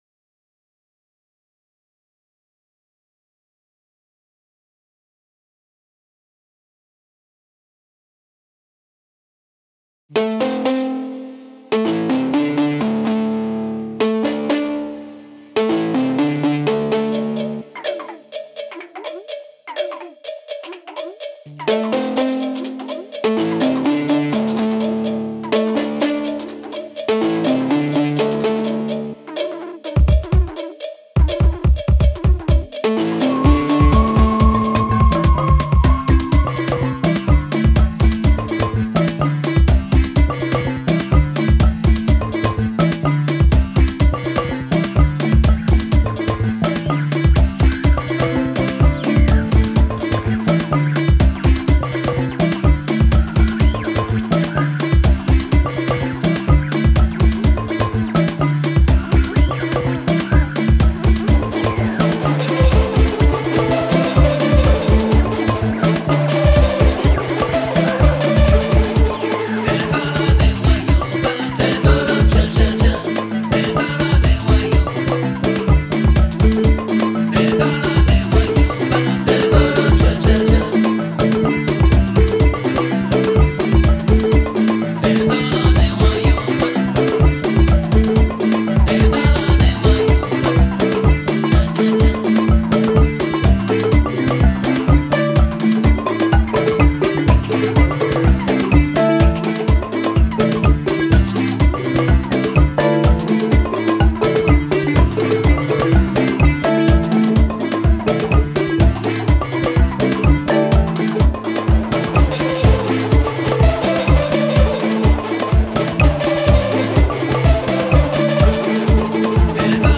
Balafon dioula, bwamou, chant
et percussions
Balafon, djembé, dunun
et chant